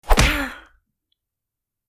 Tiếng Cú Đấm (giọng nữ)
Tiếng Đánh vào cơ thể Bịch… Tiếng Bốp (cú đấm)
Thể loại: Đánh nhau, vũ khí
tieng-cu-dam-giong-nu-www_tiengdong_com.mp3